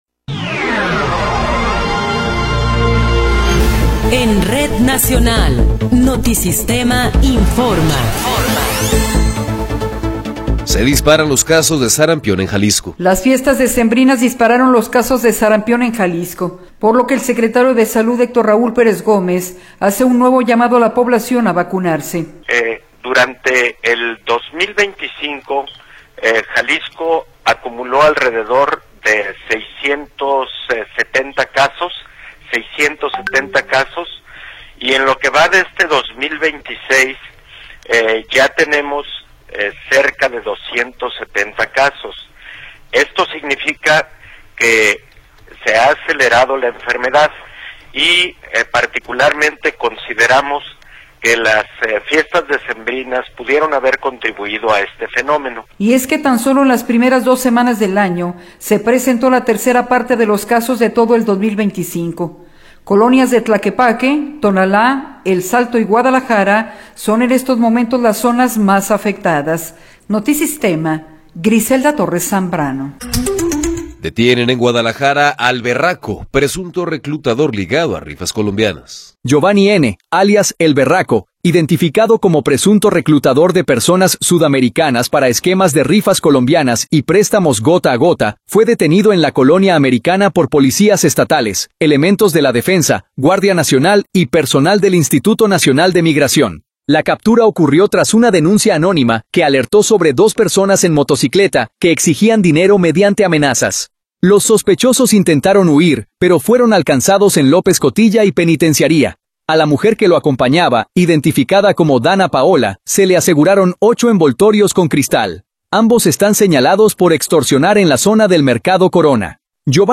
Noticiero 12 hrs. – 16 de Enero de 2026
Resumen informativo Notisistema, la mejor y más completa información cada hora en la hora.